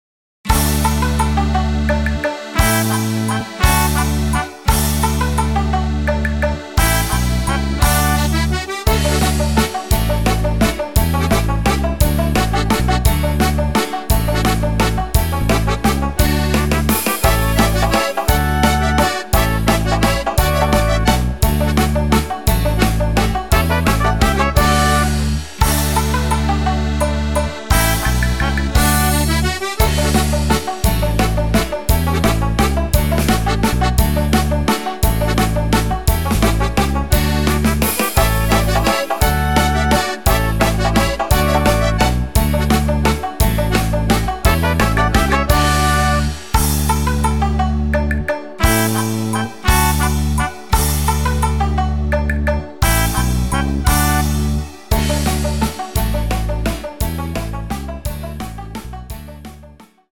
Rhythmus  Waltz
Art  Traditionell, Volkstümlich